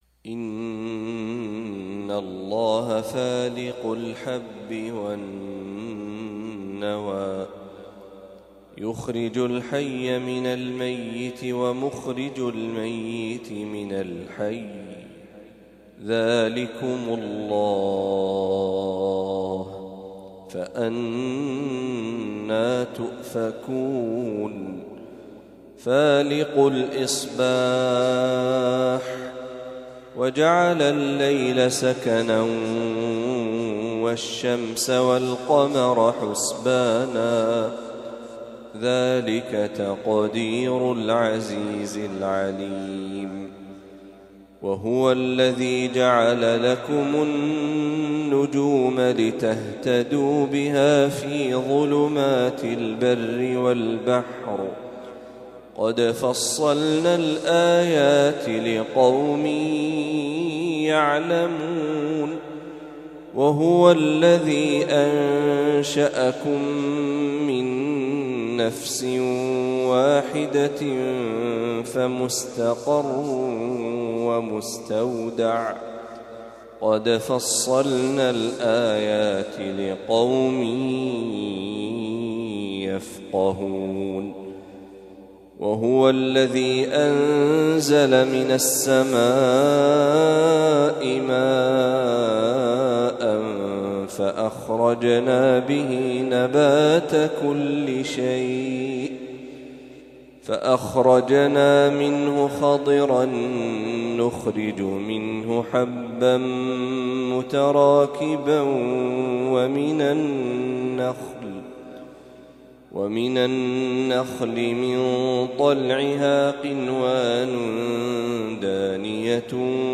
ما تيسر من سورة الأنعام | فجر الثلاثاء ٢١ ربيع الأول ١٤٤٦هـ > 1446هـ > تلاوات الشيخ محمد برهجي > المزيد - تلاوات الحرمين